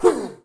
Sound / sound / monster / skeleton_magician / damage_1.wav
damage_1.wav